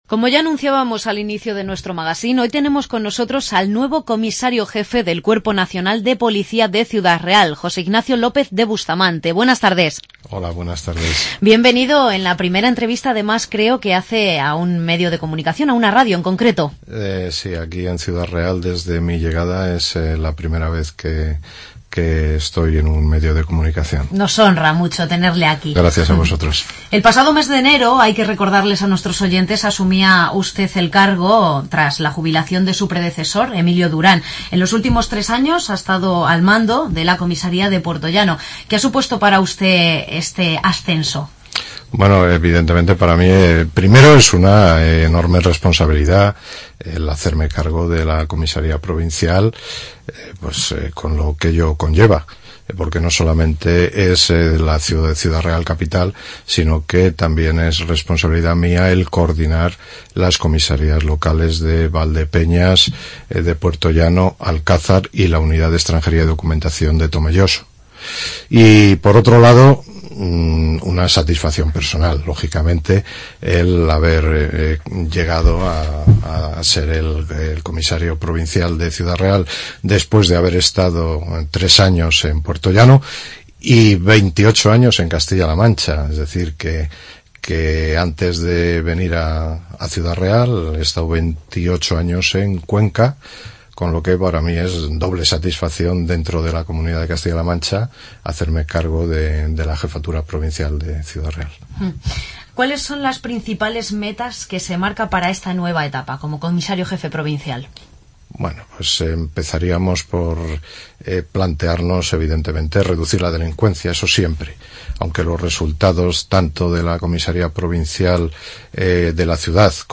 Hoy ha estado en LA MAÑANA DE COPE CR José Ignacio López de Bustamante, nuevo Comisario Jefe provincial de la Policía Nacional de Ciudad Real, en la primera entrevista que concede a un medio de comunicación tras tomar posesión del cargo.